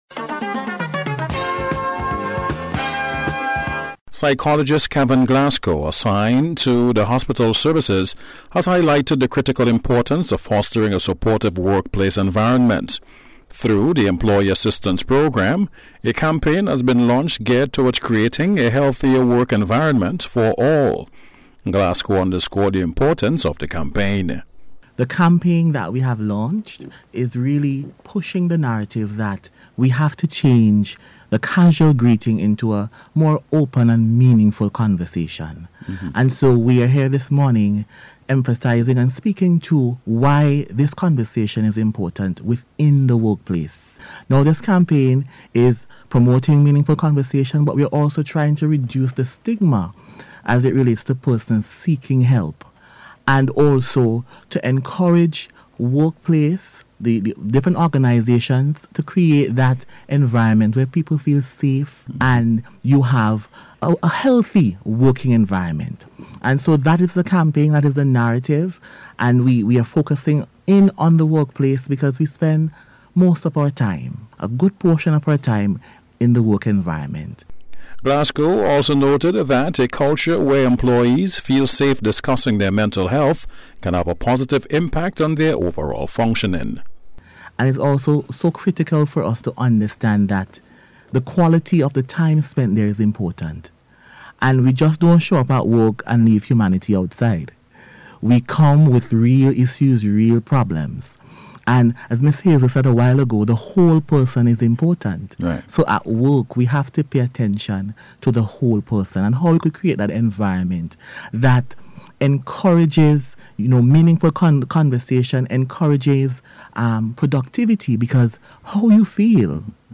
NBC’s Special Report- Friday 17th April, 2026